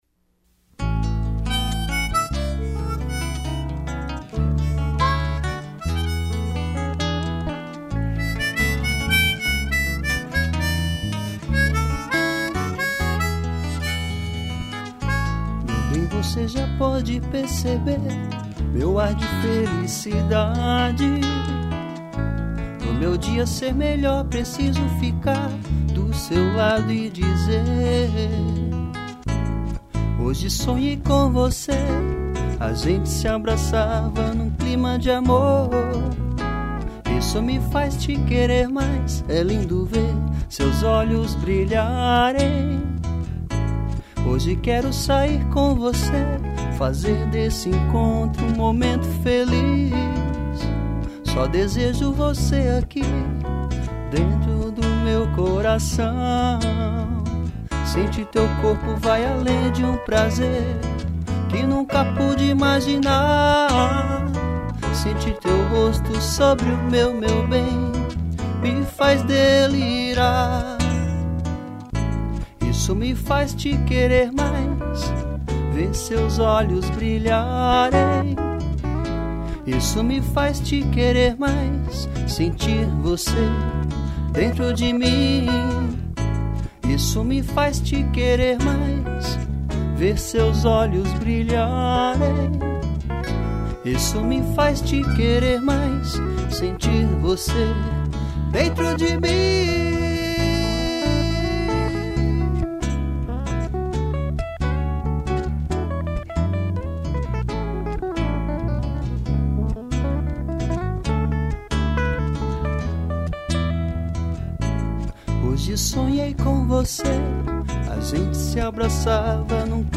voz
violão
gaita